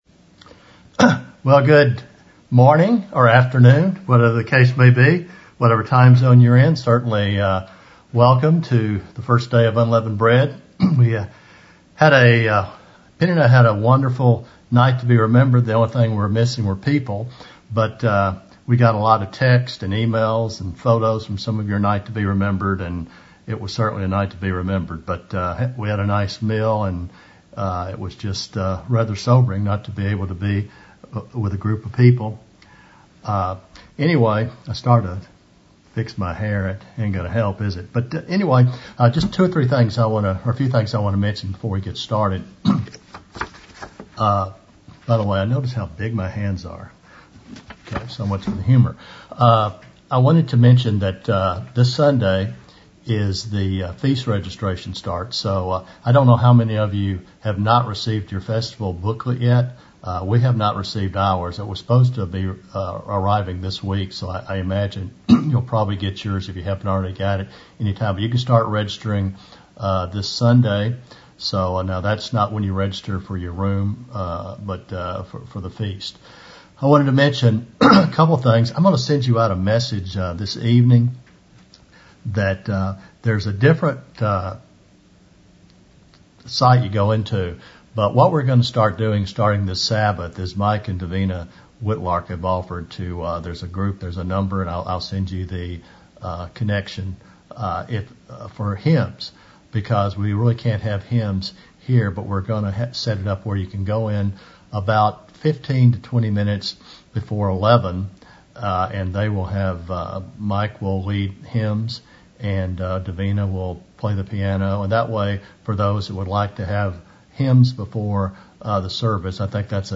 Given in Dallas, TX Fort Worth, TX
Holy Day Services Studying the bible?